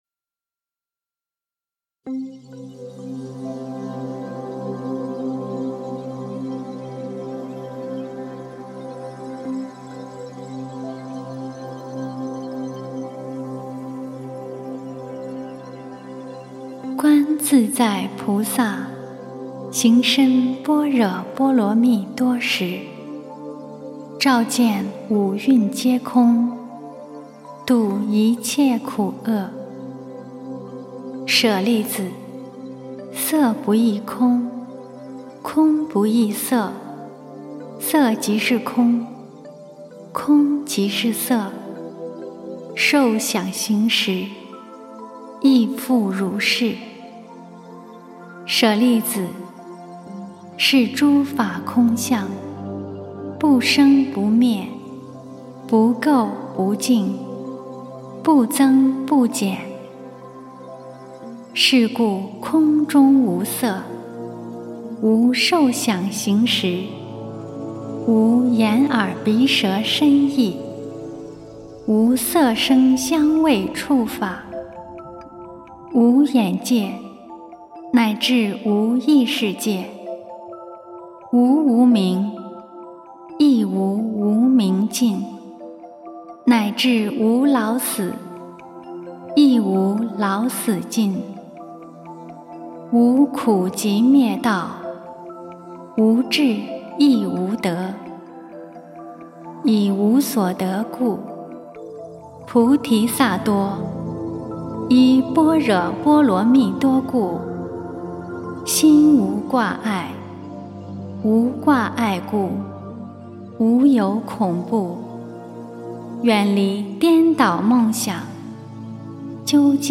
心经（念诵）
诵经
佛音 诵经 佛教音乐 返回列表 上一篇： 心经 下一篇： 心经 相关文章 职场35利合同均--佛音大家唱 职场35利合同均--佛音大家唱...